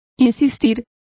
Complete with pronunciation of the translation of insisting.